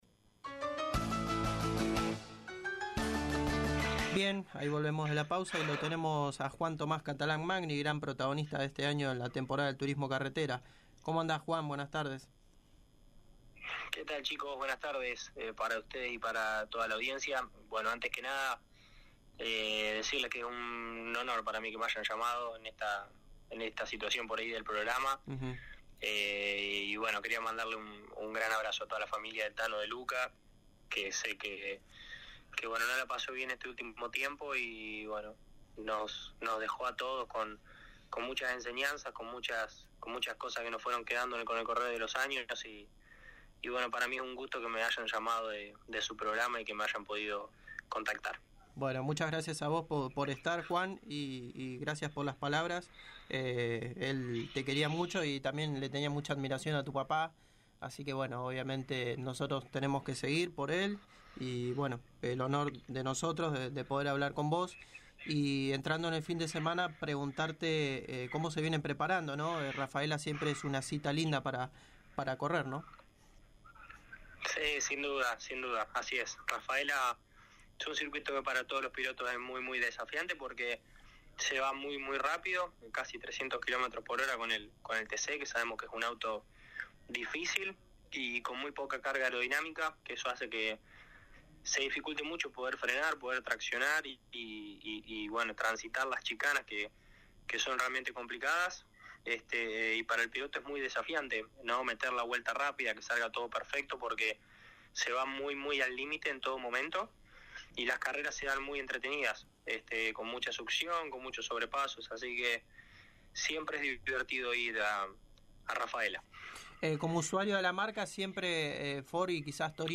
El arrecifeño pasó por los micrófonos de Pole Position y detalló como se vienen preparando para Rafaela y destacó la realidad que vive el conjunto en las categorías de la ACTC.